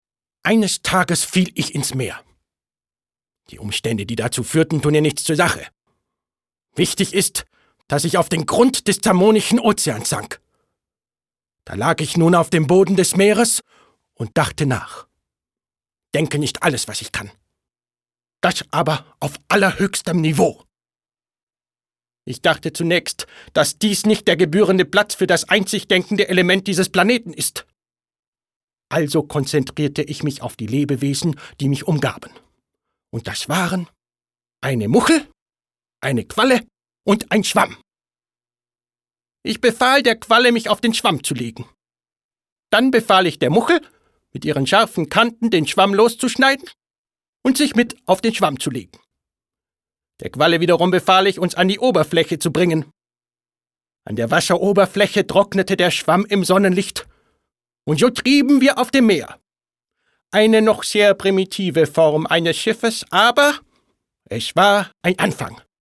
deutscher Sprecher und Schauspieler.
Sprechprobe: Sonstiges (Muttersprache):